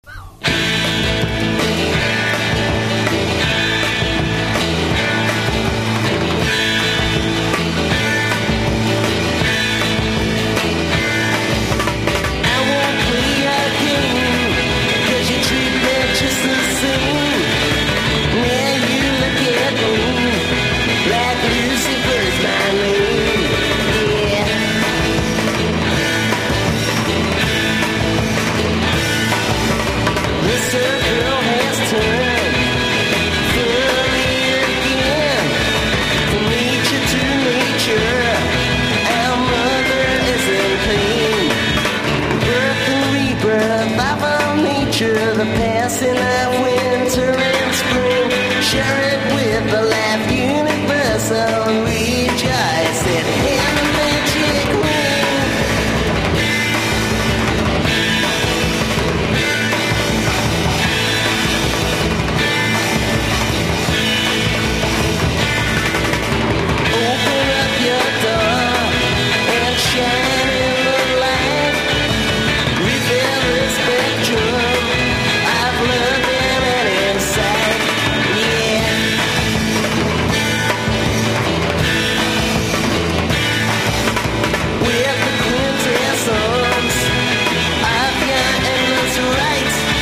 1. 60'S ROCK >
# GARAGE ROCK